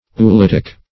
Oolitic \O`o*lit"ic\, a. [Cf. F. oolithique.]